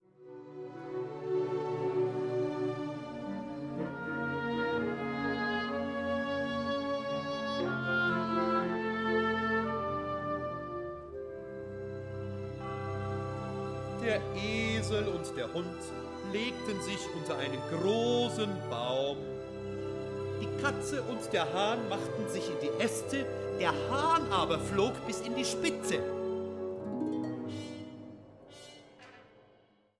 Konzerte für Sprecher und Orchester